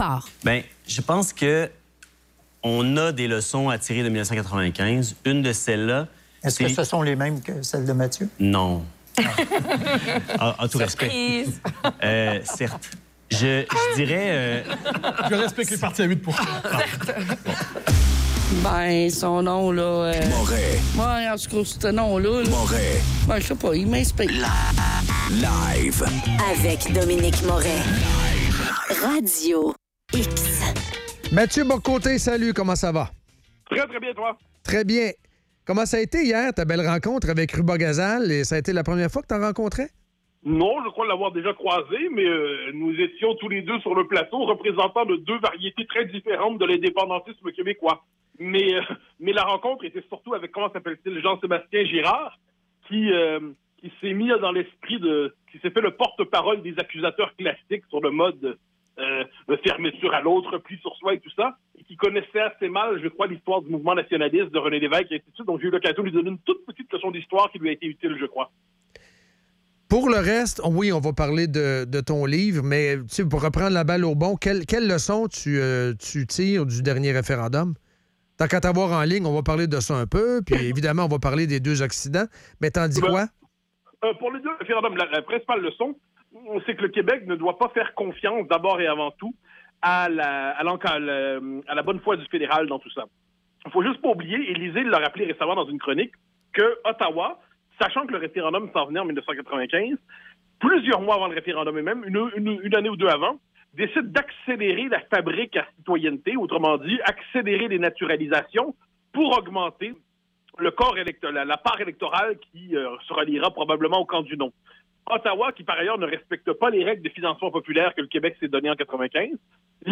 Entrevue avec Mathieu Bock-Côté